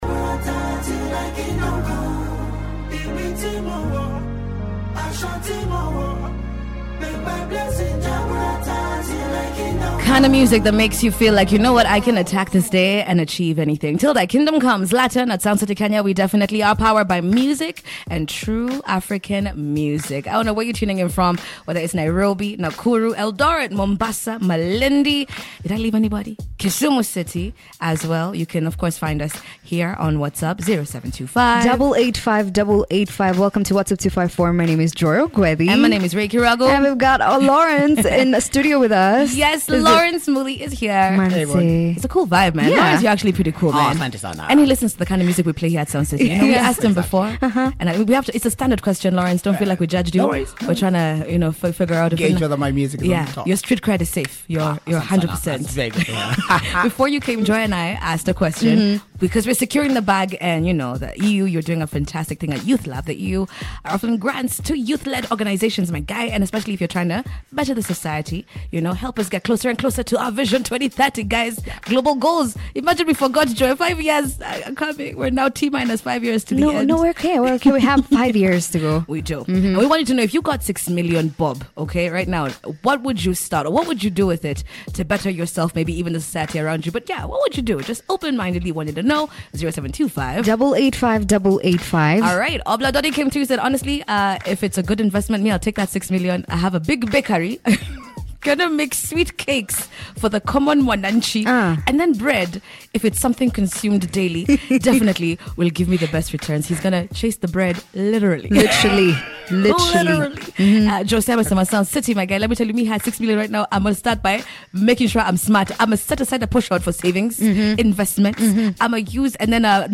INTERVIEW-LINK-2.mp3